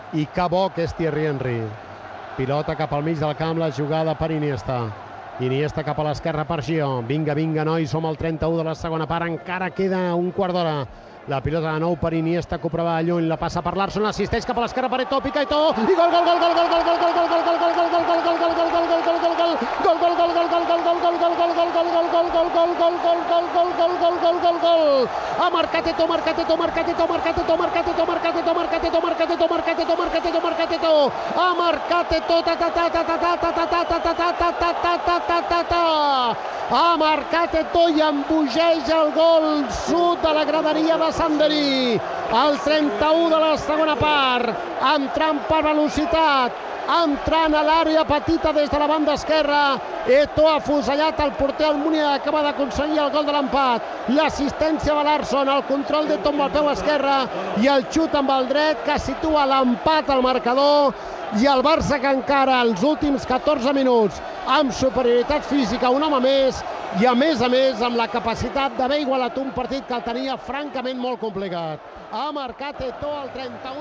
Final de la Copa d'Europa de Futbol masculí 2005-2006 entre el Barça i l'Arsenal, a París. Narració del gol de Samuel Eto'o que empata el partit a la segona part.
Esportiu